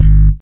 Bass (11).wav